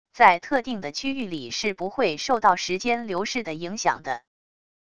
在特定的区域里是不会受到时间流逝的影响的wav音频生成系统WAV Audio Player